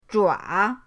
zhua3.mp3